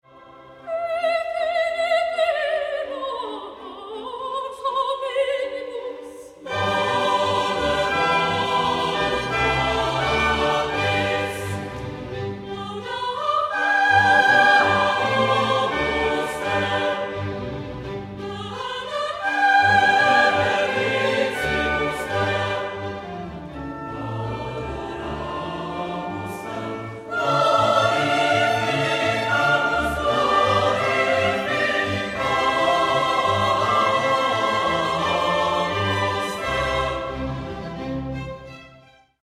Smíšený pěvecký sbor Rastislav Blansko
V případě zájmu o získání CD nosičů nás prosím kontaktujte na emailové adrese rastislav (zavináč) rastislav (tečka) cz Missa Dominicalis in C, František Xaver Brixi Nahráno v Rytířském sále Nové radnice v Brně dne 12. října 2003.
varhany
Petra a Pavla Brno Sbor: Smíšený pěvecký sbor Rastislav Blansko Dirigent